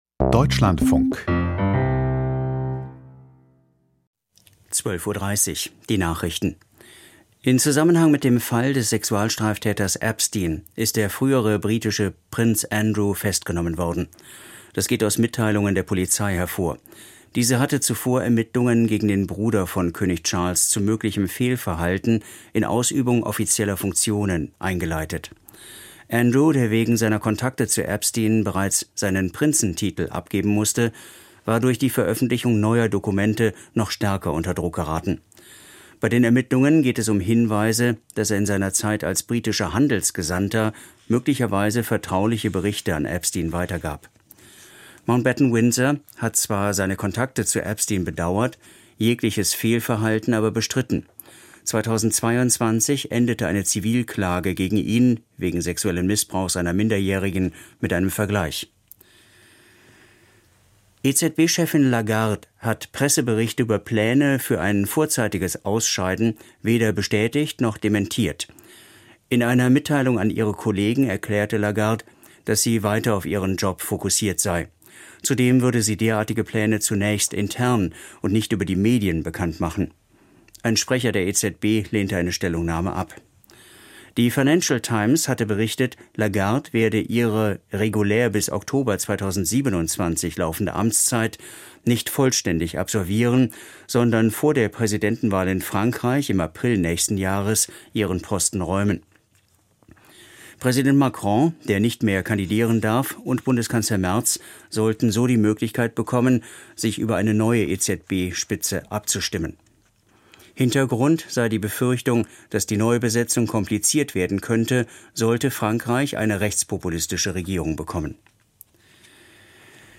Die Nachrichten vom 19.02.2026, 12:30 Uhr